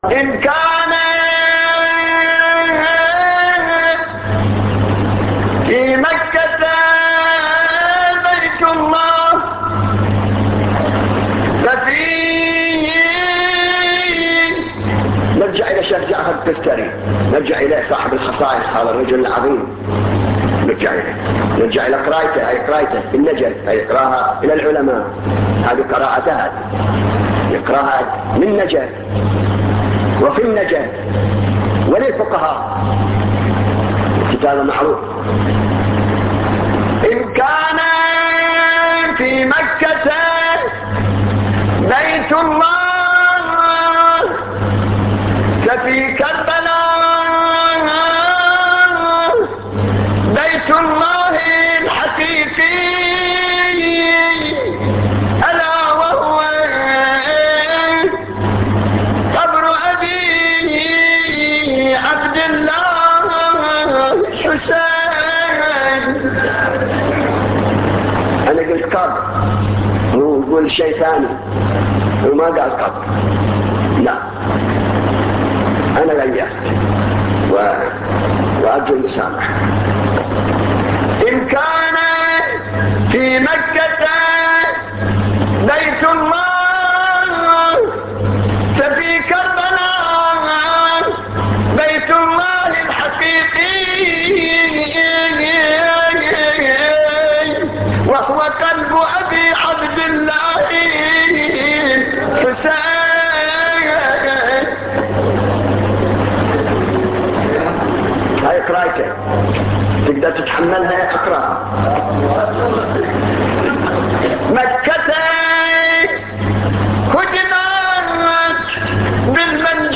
نواعي حسينية 14